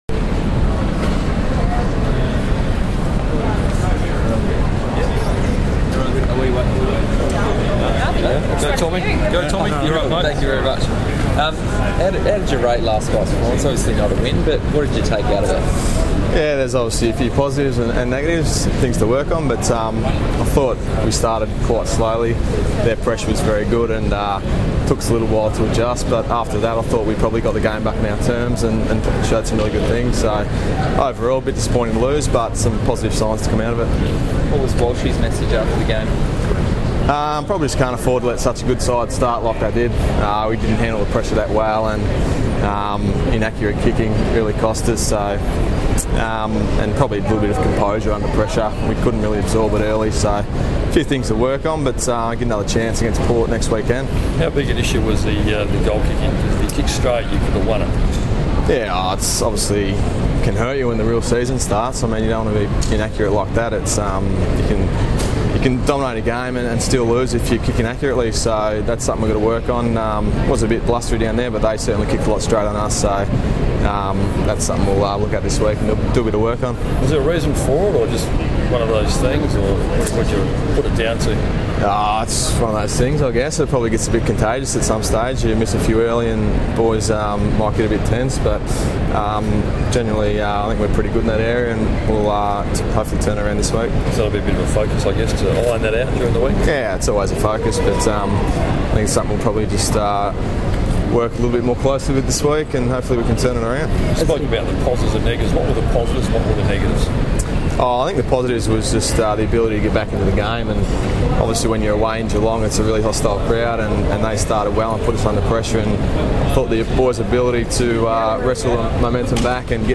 Richard Douglas Press Conference